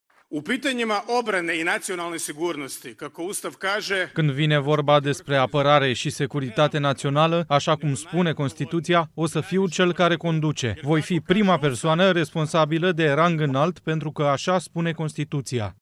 Într-un discurs rostit după publicarea rezultatelor, Milanovic a declarat că victoria sa a fost un semn de aprobare şi încredere din partea alegătorilor.
13ian-15-Milanovic-reales-presedinte-discurs.mp3